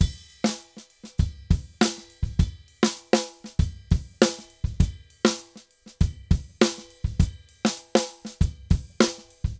Drums_Candombe 100_3.wav